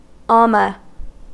Ääntäminen
Vaihtoehtoiset kirjoitusmuodot almah Synonyymit almeh Ääntäminen US Tuntematon aksentti: IPA : /ˈælmə/ Haettu sana löytyi näillä lähdekielillä: englanti Käännöksiä ei löytynyt valitulle kohdekielelle.